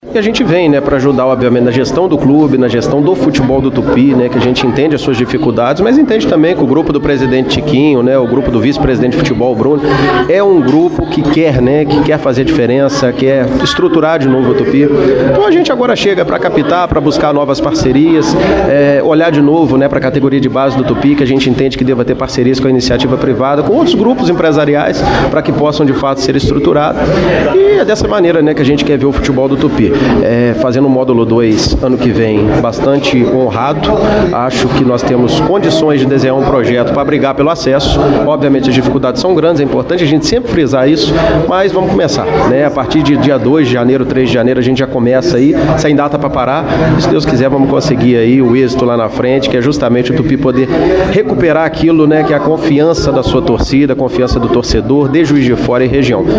Ele disse que o trabalho será conjunto e com foco de restabelecer o futebol profissional e de base do clube